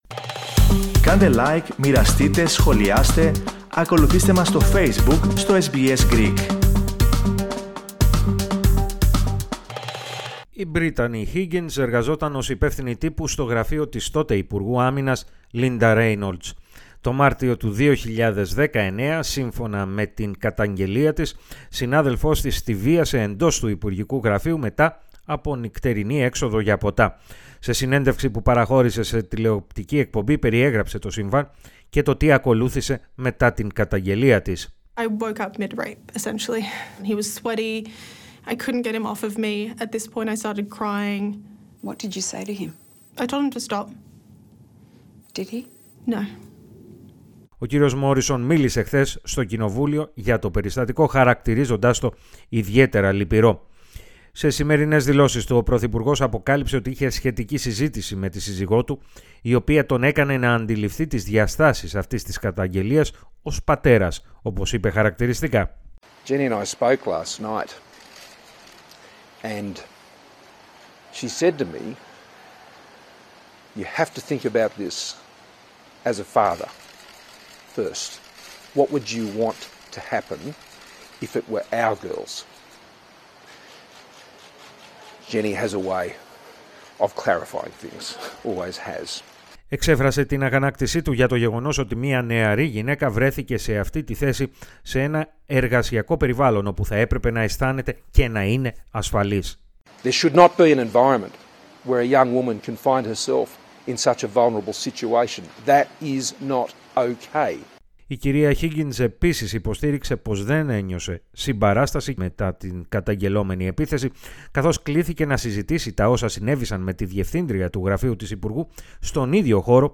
Ο πρωθυπουργός Σκοτ Μόρισον, ζήτησε συγγνώμη από την πρώην εργαζόμενη του Φιλελεύθερου Κόμματος, Μπρίτανι Χίγκινς, για τον τρόπο με τον οποίο αντιμετωπίστηκε η καταγγελία της. Περισσότερα ακούστε στην αναφορά